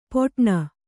♪ poṭṇa